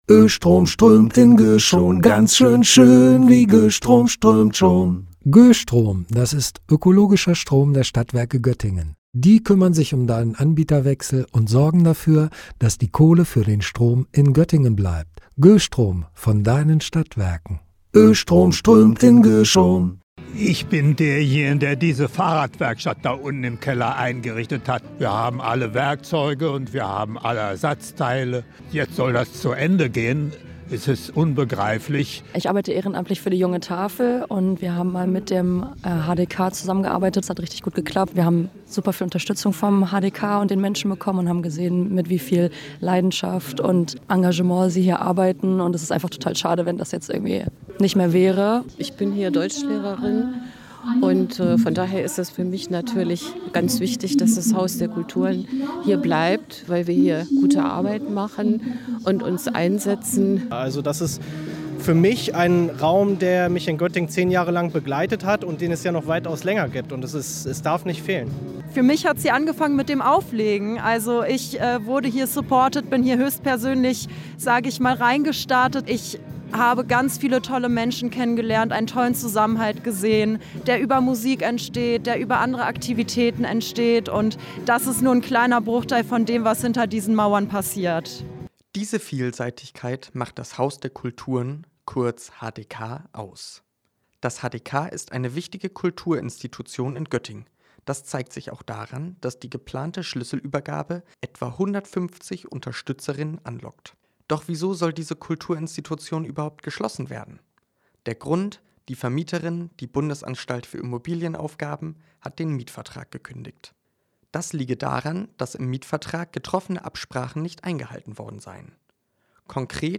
Deswegen versammelten sich rund 150 Unterstützer*innen der Einrichtung am Gebäude im Hagenweg.